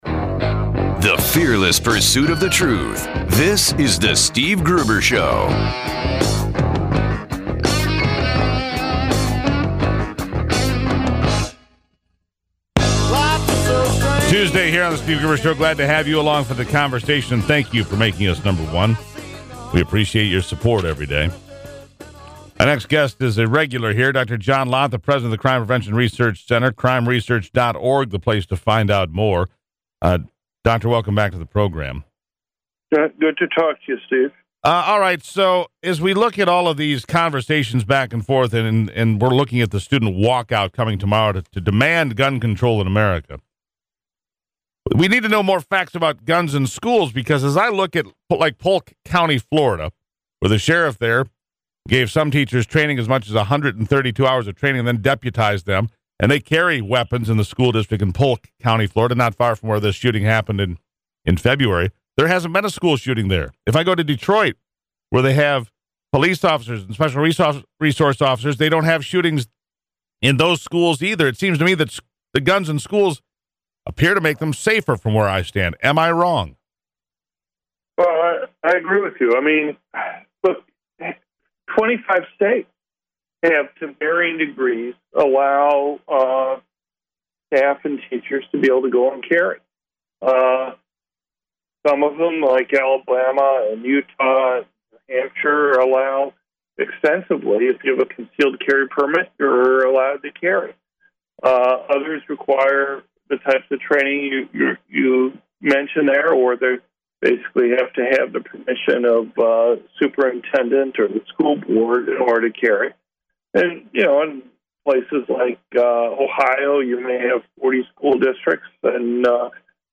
media appearance